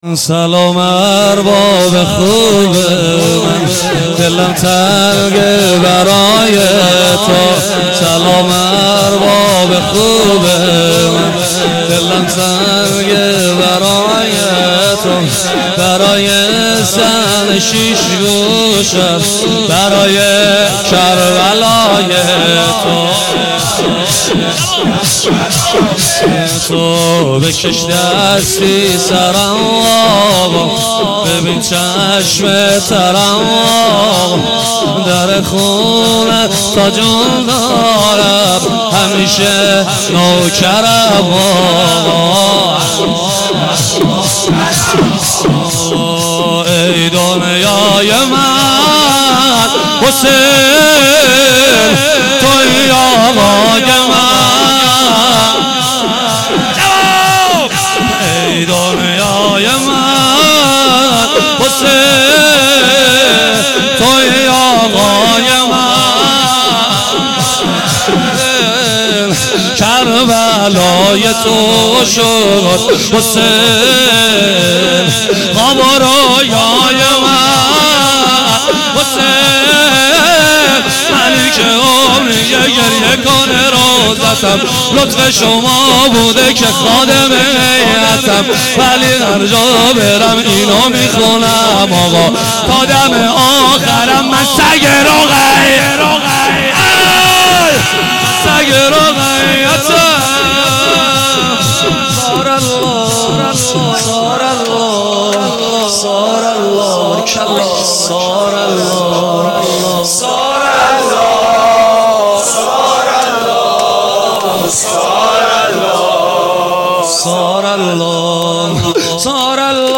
شور سلام ارباب خوب
وفات حضرت ام البنین (س)